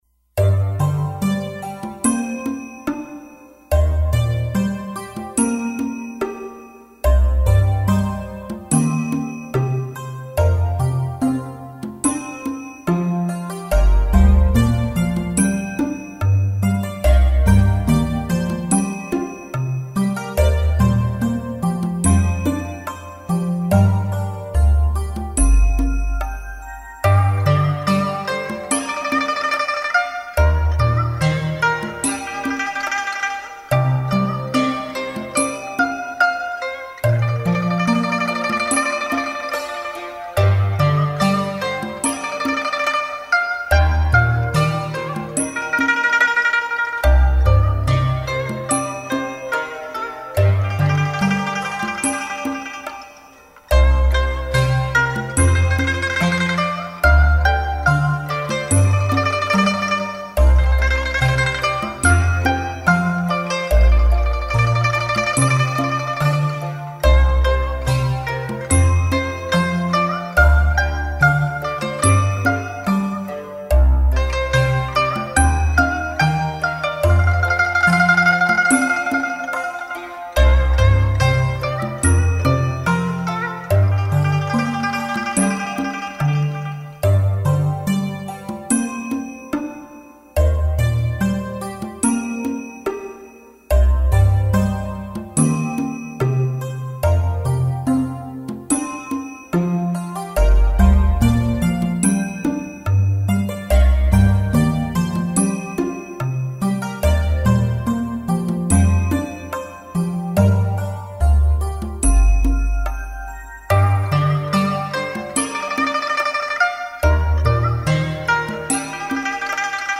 Chùa Hạnh Đức - Nhạc Thiền